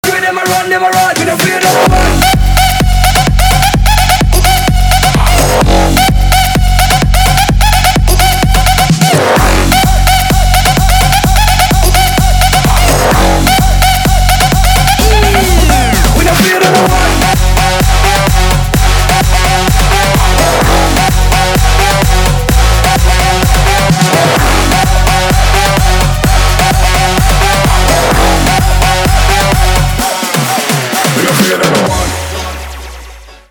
• Качество: 320, Stereo
electro house
бодренький трек